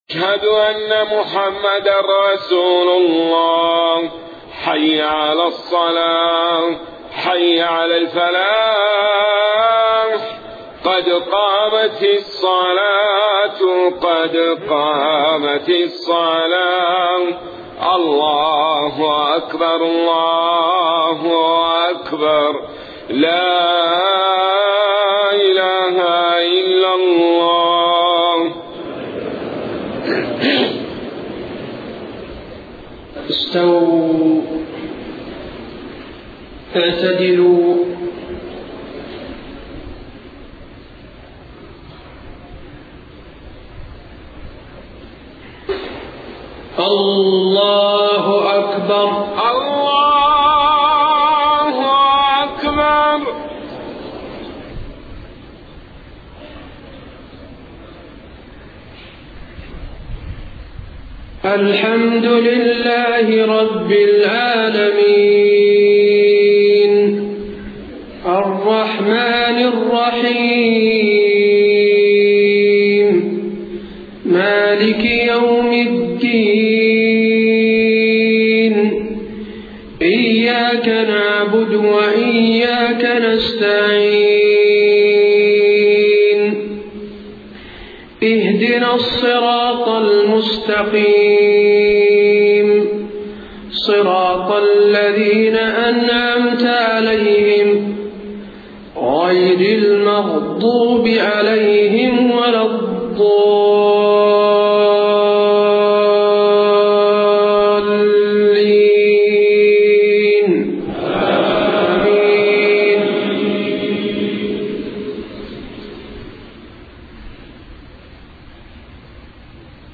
صلاة المغرب 12 ربيع الأول 1431هـ خواتيم سورة القمر 47-55 و الكوثر > 1431 🕌 > الفروض - تلاوات الحرمين